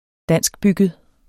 Udtale [ -ˌbygəð ]